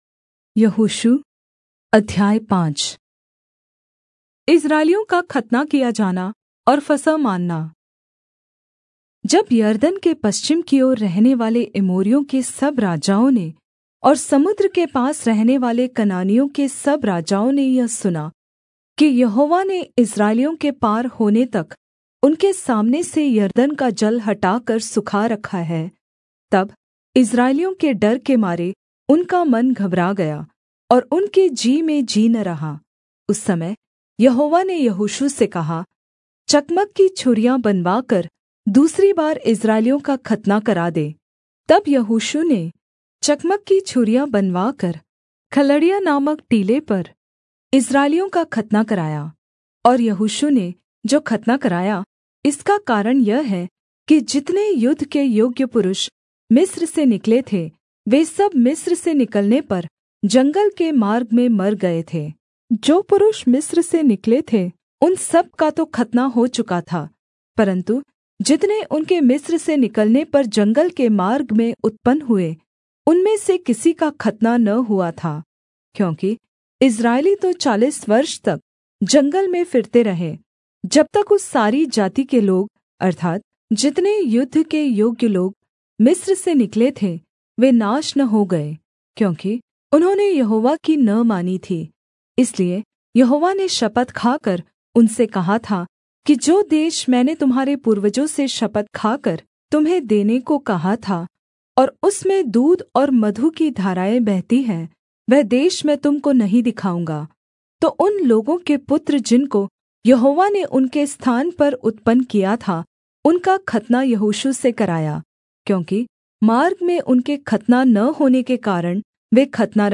Hindi Audio Bible - Joshua 5 in Irvhi bible version